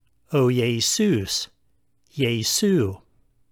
You can pronounce the Greek name as either ee-ay-SOUS or yea-SOUS.